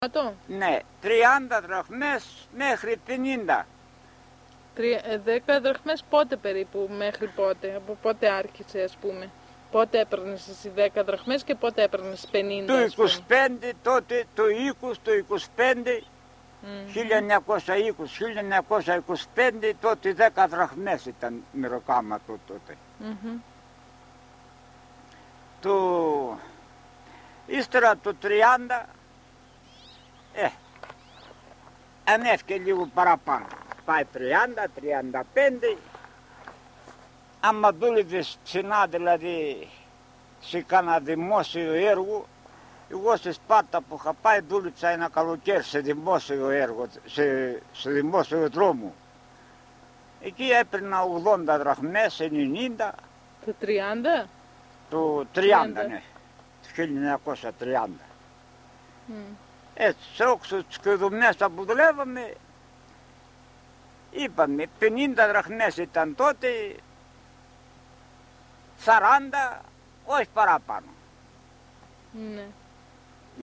Έρευνα στα Μαστοροχώρια της Δυτικής Μακεδονίας, από το επιστημονικό προσωπικό του ΛΕΜΜ-Θ.
Συνέντευξη με ηλικιωμένο άντρα, που άσκησε το επάγγελμα του μάστορα- χτίστη ("καρφά"): οι αμοιβές των χτιστάδων. (EL)